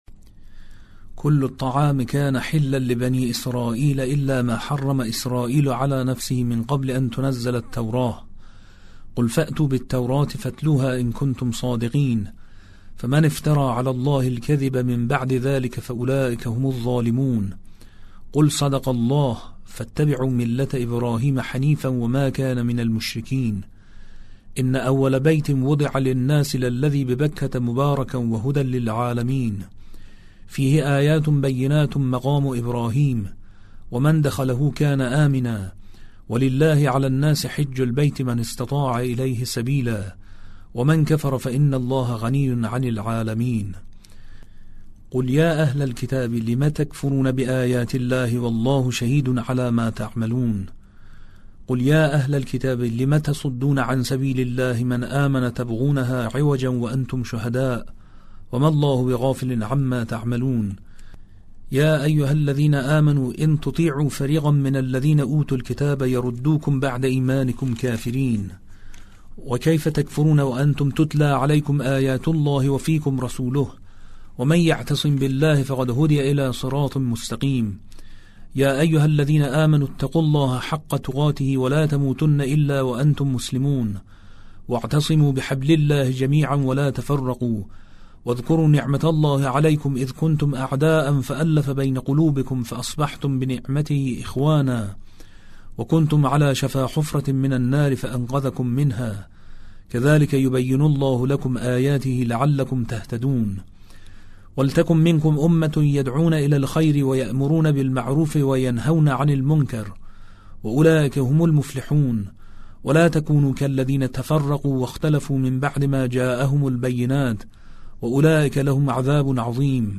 جز خوانی - جز 4 - ماه رمضان97